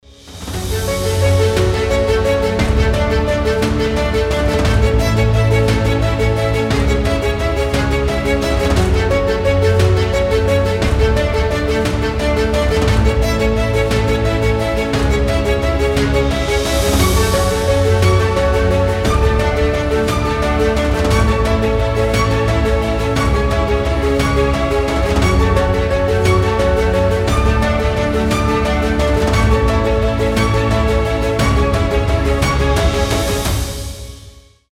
• Качество: 320, Stereo
без слов
красивая мелодия
скрипка
оркестр
New Age
эпичные
Чудесная мелодия, как из какого-нибудь фильма